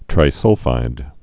(trī-sŭlfīd)